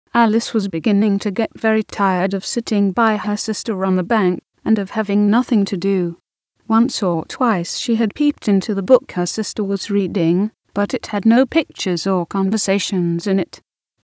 (1)特徴 声質は人間の声にかなり近く、人口音声らしさをかなり排除できている。 イントネーションが時々、妙なところがある。